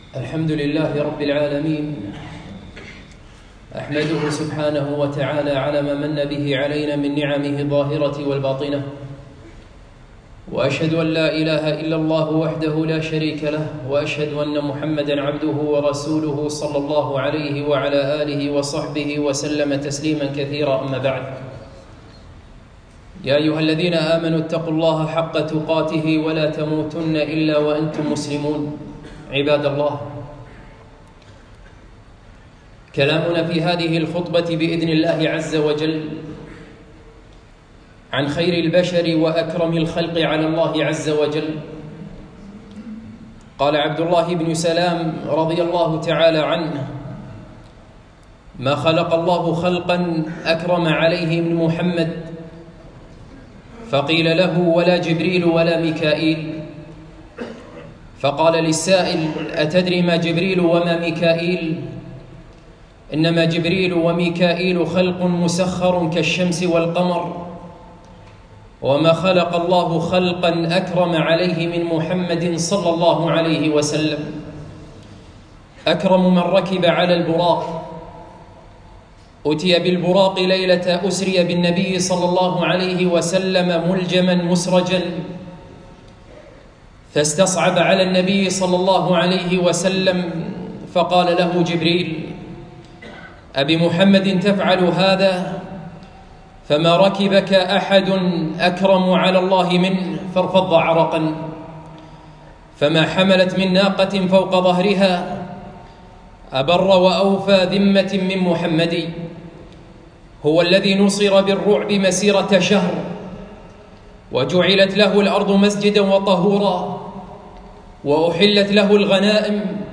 خطبة - محمد رسول الله ﷺ بين الغلو والجفاء